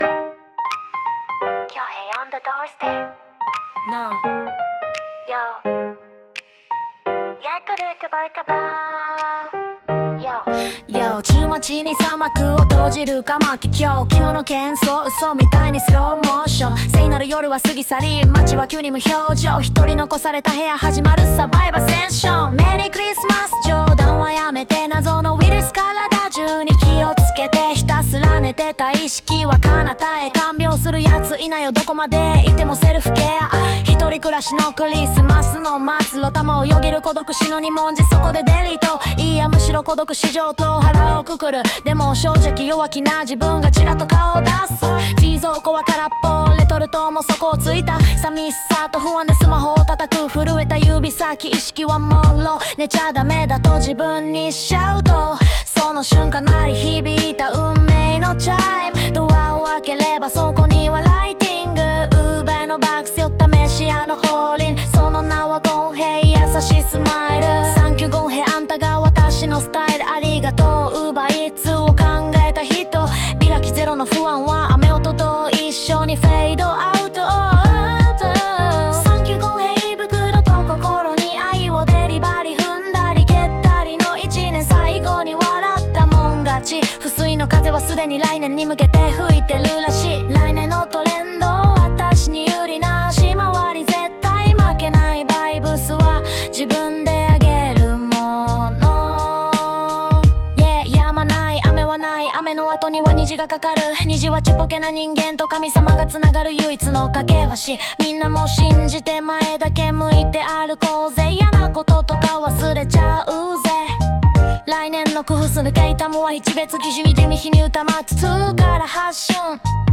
作曲　Lewis (AI)